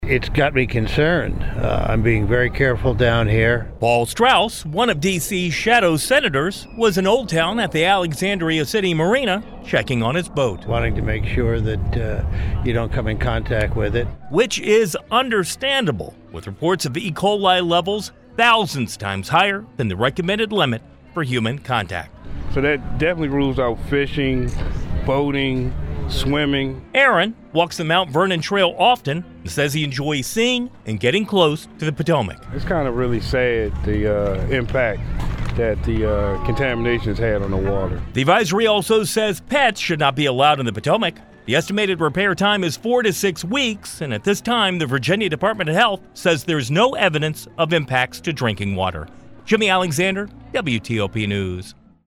reports from Alexandria after a recreational water advisory was issued following a raw sewage spill in the Potomac.